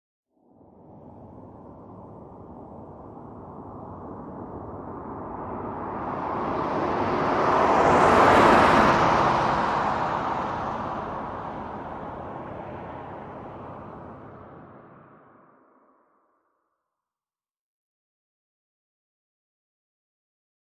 Corvette; By, Medium; Medium By With Heavy Sound Of Tires On Pavement. Medium Perspective. Sports Car, Auto.